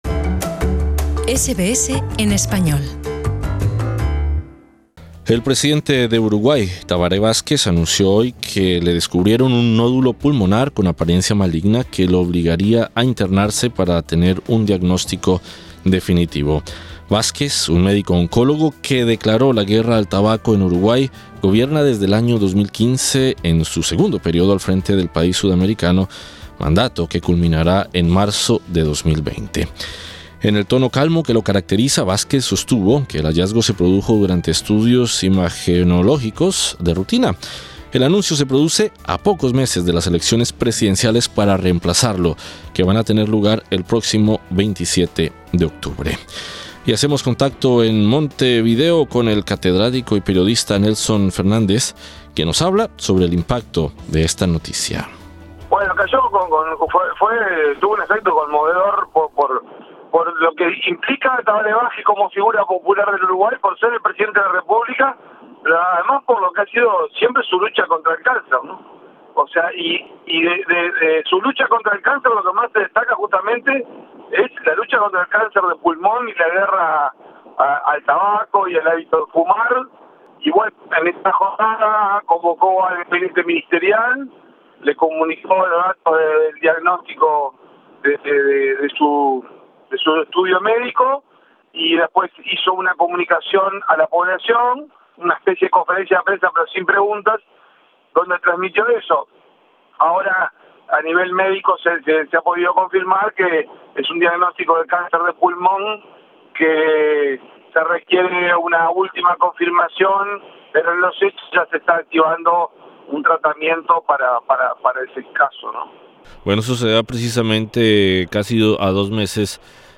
Entrevista en Montevideo